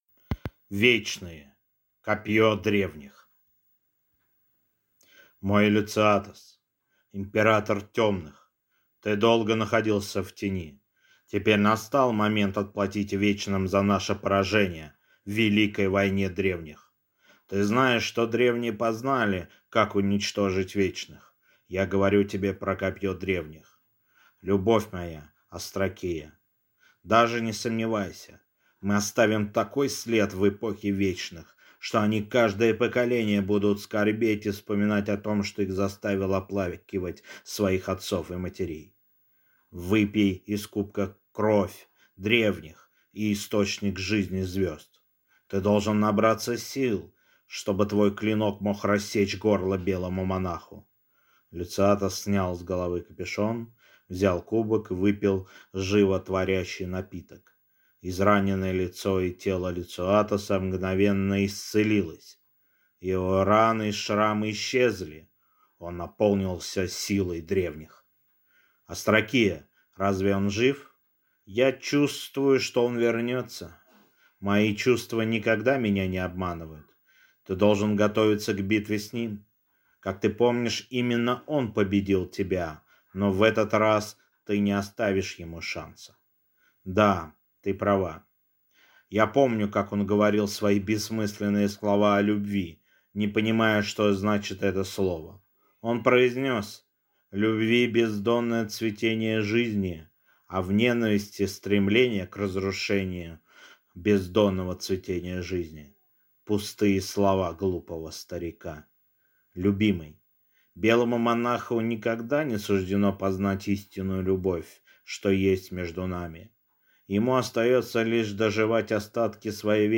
Аудиокнига Вечные. Копьё Древних | Библиотека аудиокниг